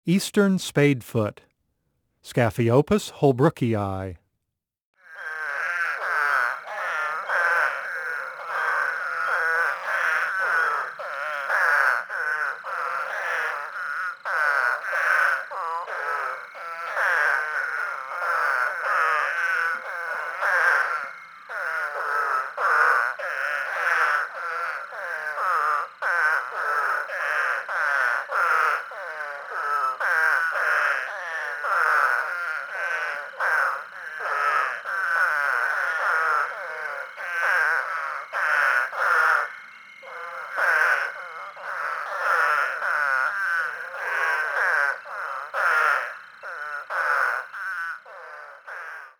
Eastern Spadefoot
The eastern spadefoot makes a loud, nasal aaaah sound lasting about one to five seconds.